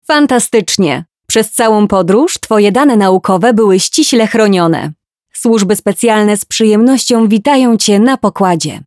Kommerziell, Natürlich, Freundlich, Warm, Sanft
E-learning